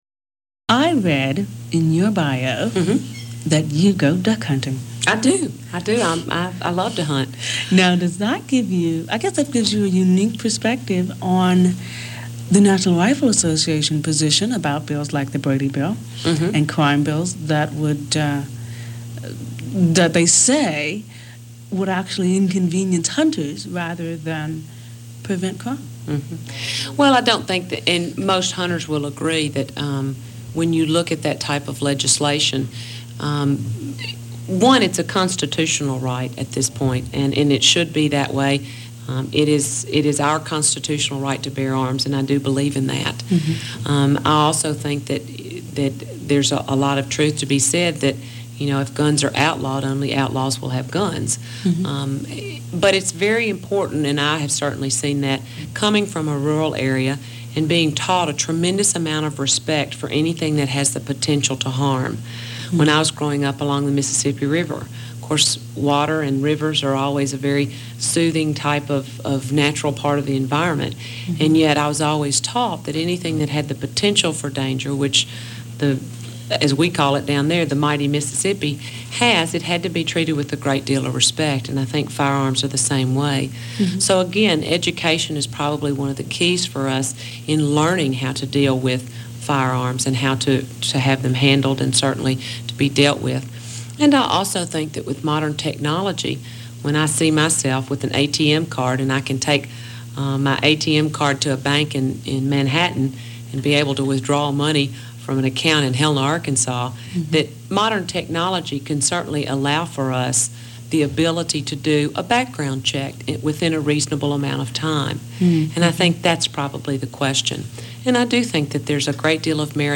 Here you will find two new features plus segments from different talk show interviews.